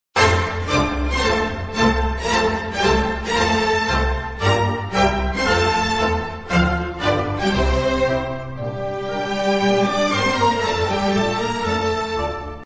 进行曲音效